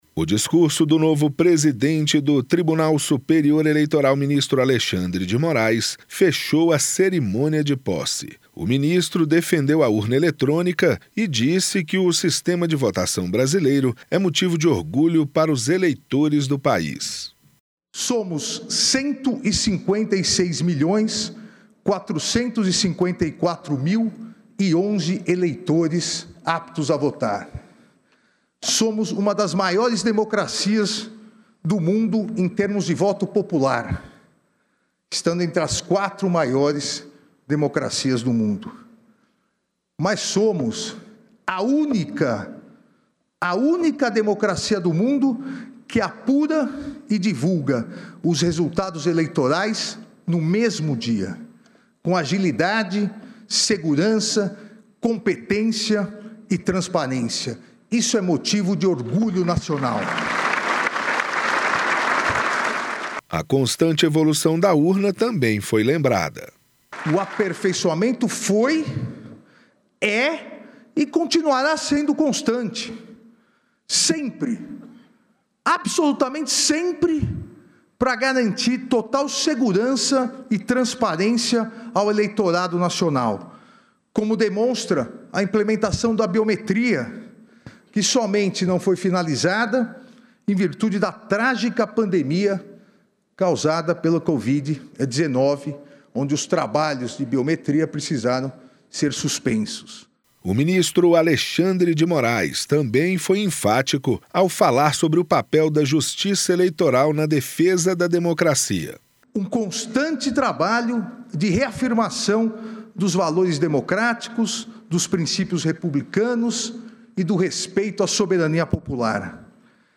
No discurso de posse, o ministro destacou o papel da Justiça Eleitoral na garantia do exercício da democracia no Brasil e a importância do combate à desinformação. Ele defendeu as urnas eletrônicas e reforçou que o sistema eleitoral é orgulho nacional.